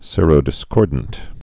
(sîrō-dĭ-skôrdnt)